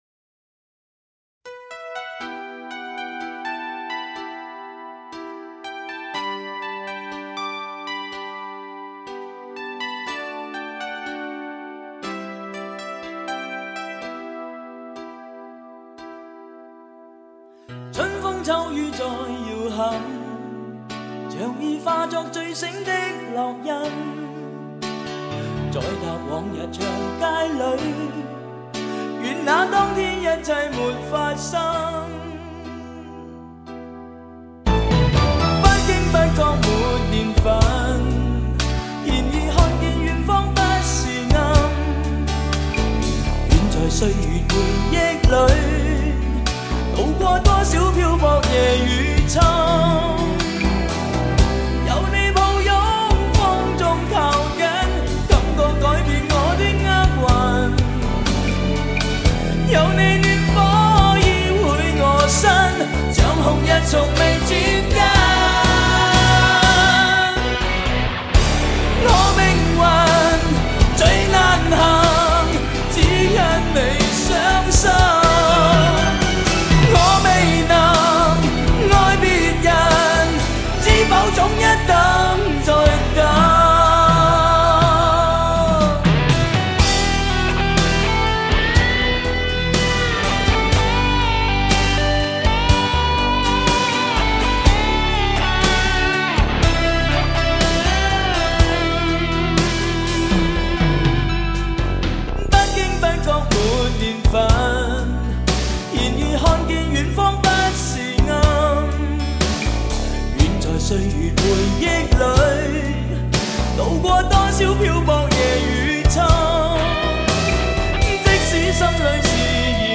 真实自然的感情流露